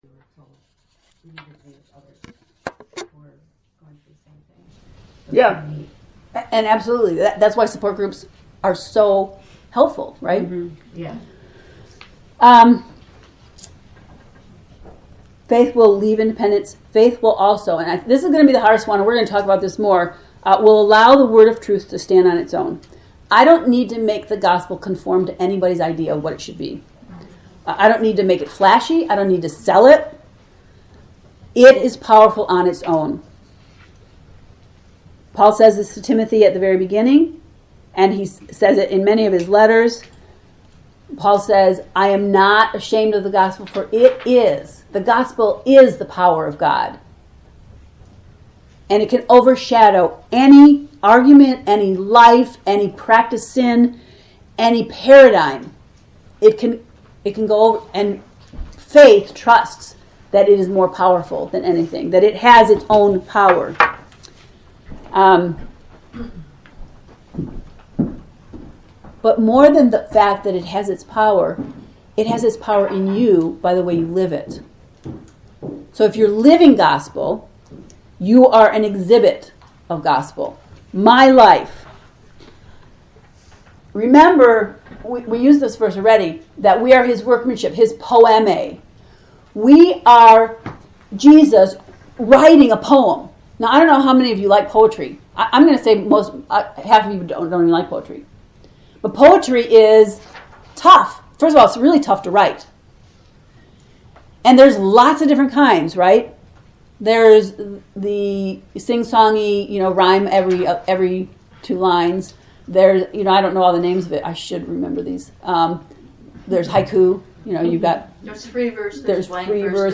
To listen to the lesson 4/5 lecture click below:  (there are 2 parts – the last part being only 3 minutes long before it is cut off to explain an experiment, but no lecture content)